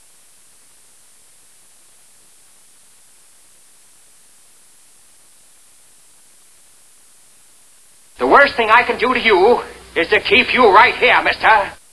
delays can be added to a sound for this one i attached i put in an 8 second delay